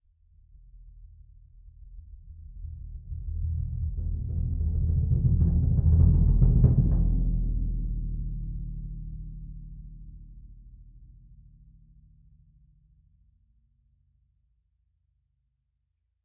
bassdrum-cresc-short.mp3